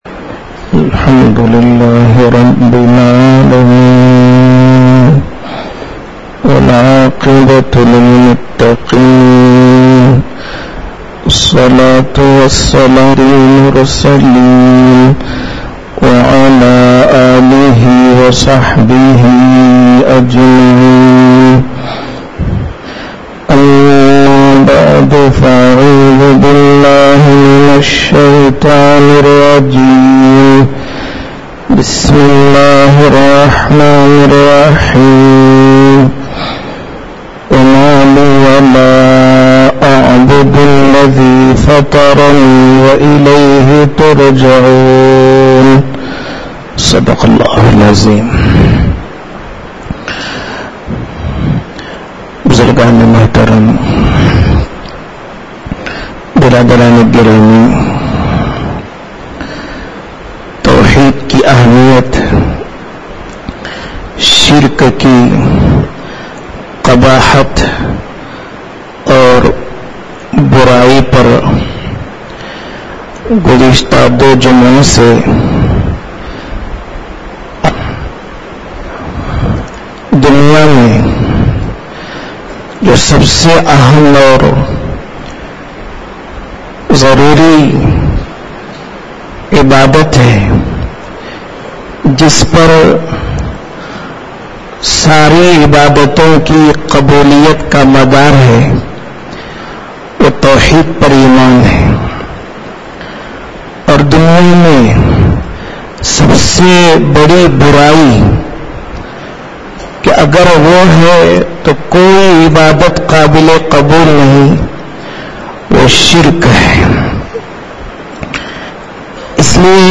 Bayaan